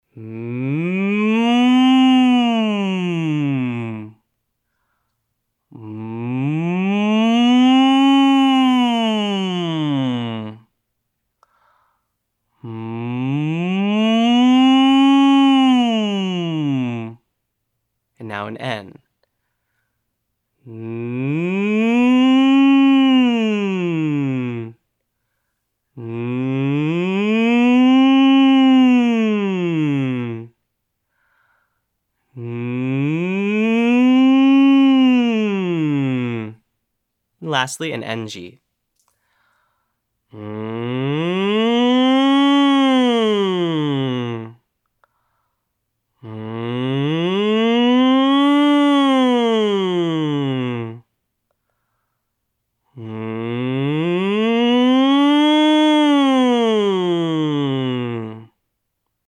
Day 2: SOVT's - Online Singing Lesson
Exercise 1: SOVT Slides
For our first exercise we will use a few common SOVTs with some gentle sirens.